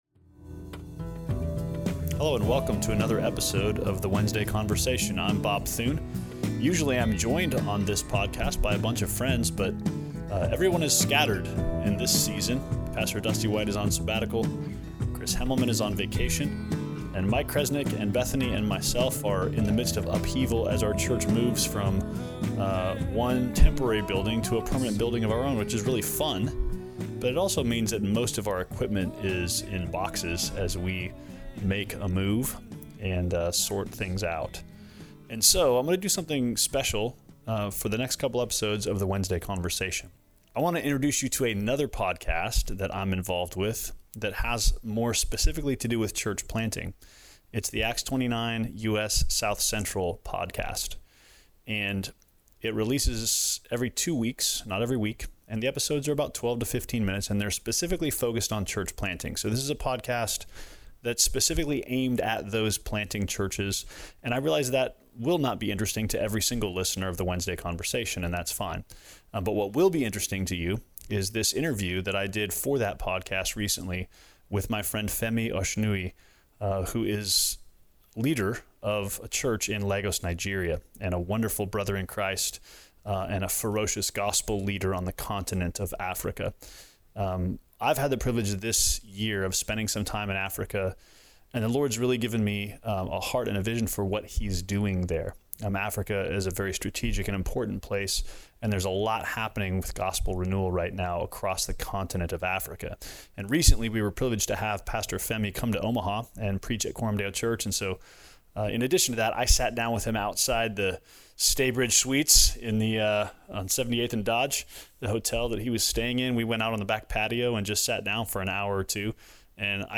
Church Planter Interview